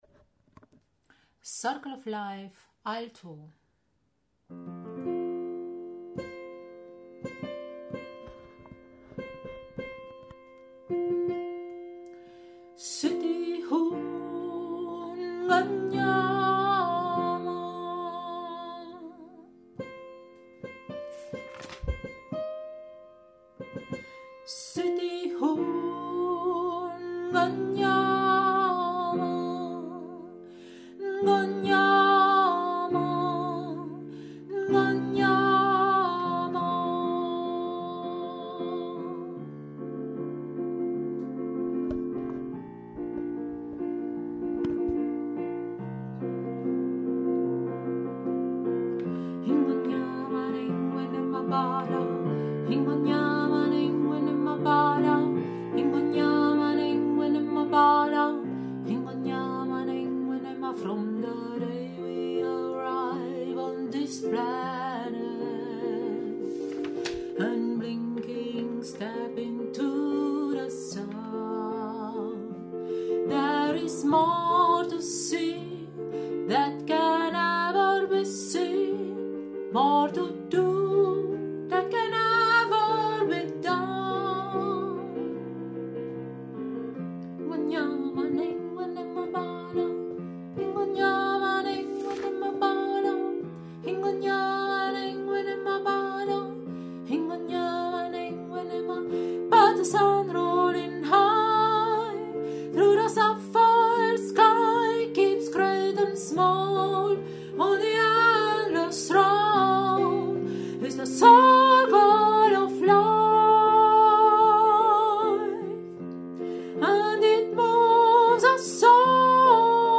Circle of Life – Alto
Circle-of-Life-Alto.mp3